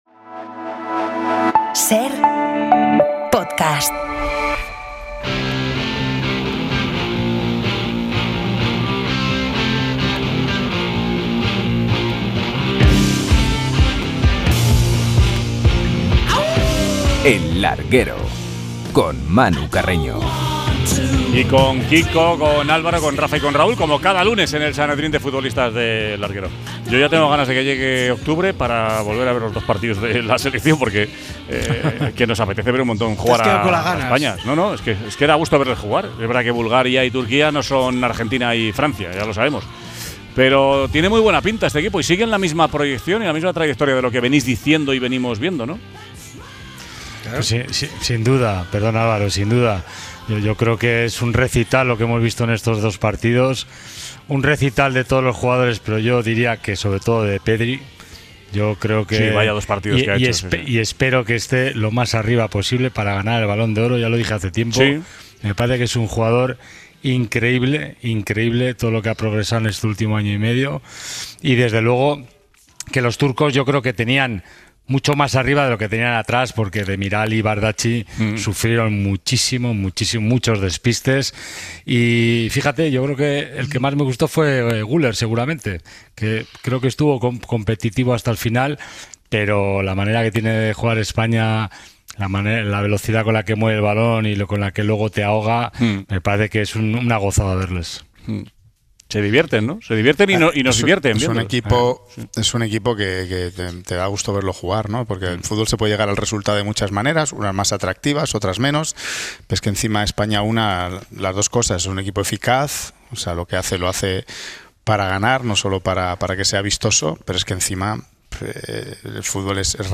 El Sanedrín de exfutbolistas repasa la goleada de España a Turquía y 10 meses de sanción a Yeray